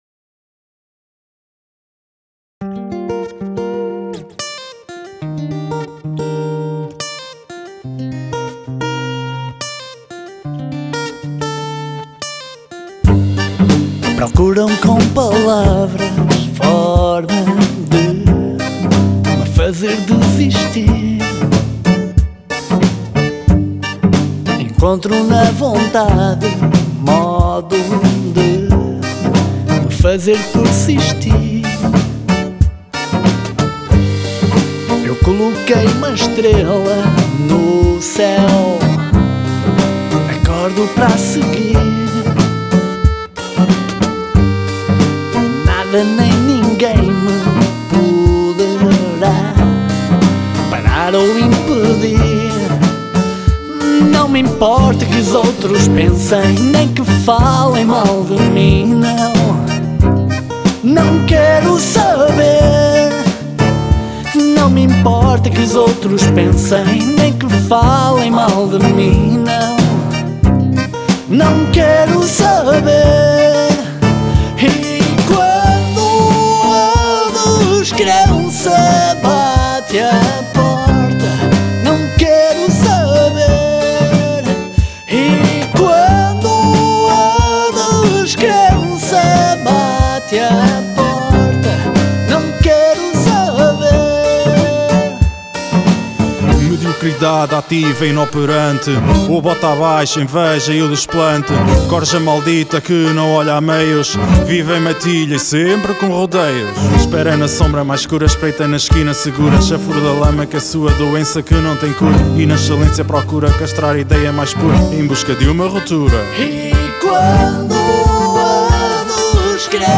cello/voice
percussion
acordeon/chorus
guitar/rap/chorus